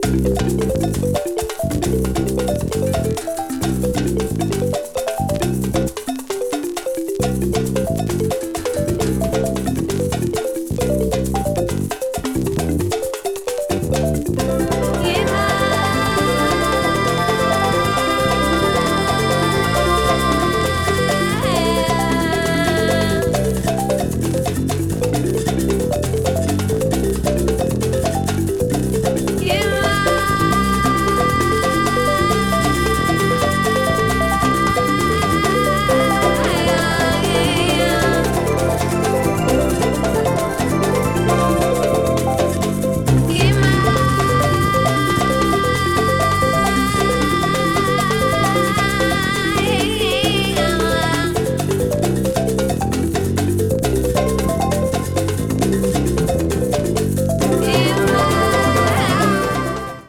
90s AMBIENT JAZZFUNK / FUSION 異世界 詳細を表示する